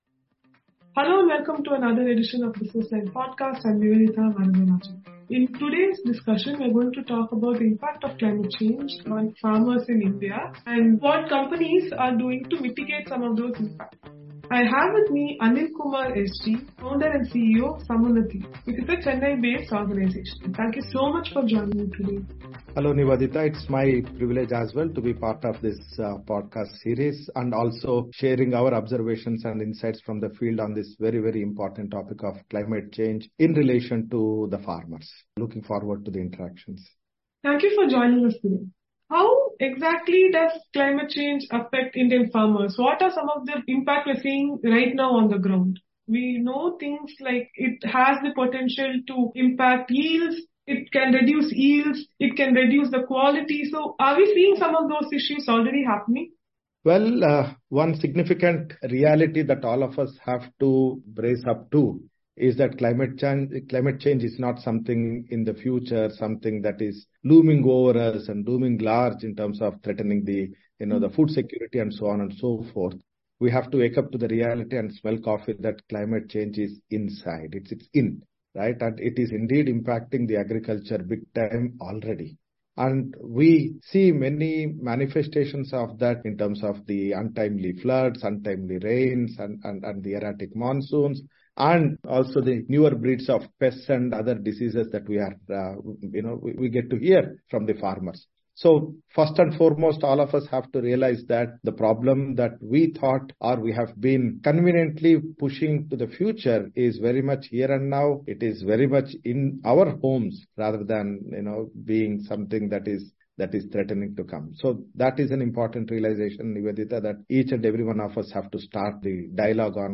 The conversation goes into detail about the awareness of Indian farmers and how the government is trying to tackle these challenges head on.